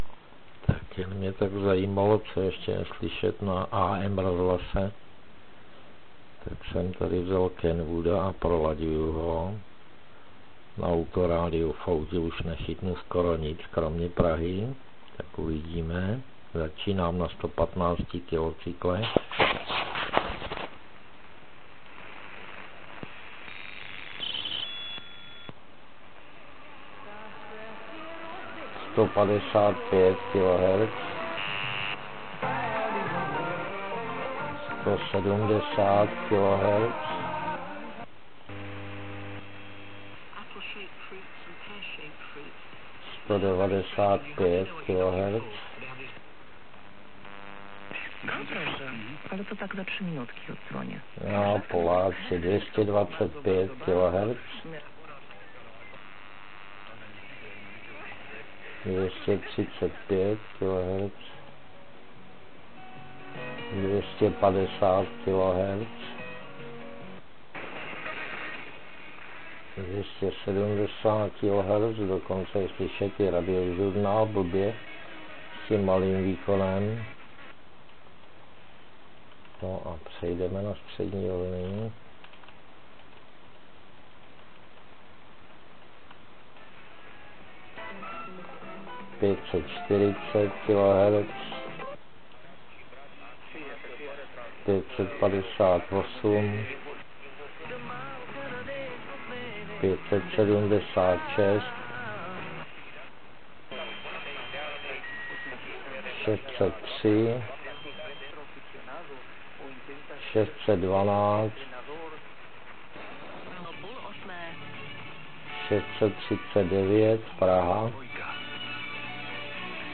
Napadlo mi jen tak projet AM pásma. Sice Kenwood TS480 na to není nejvhodnější rádio, ale nic jiného doma stejně už nemám. Zkusil jsem to jen tak proladit. Kupodivu tam je stále živo.